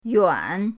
yuan3.mp3